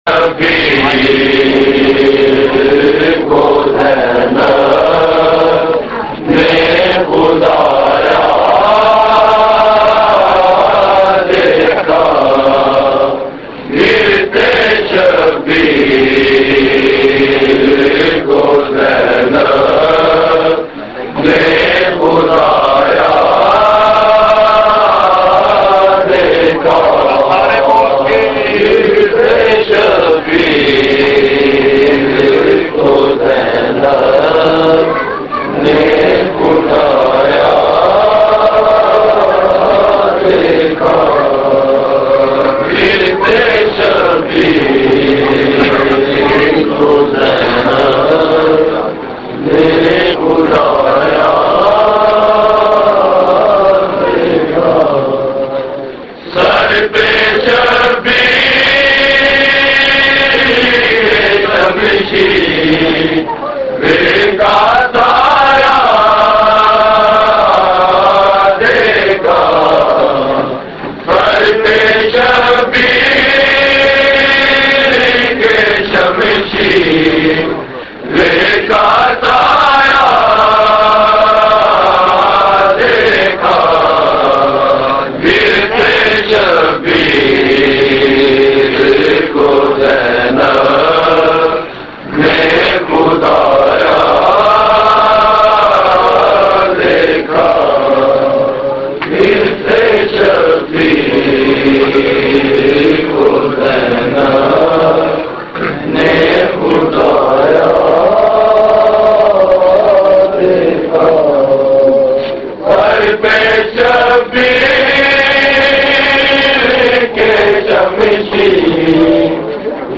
Markazi Matmi Dasta, Rawalpindi
Recording Type: Live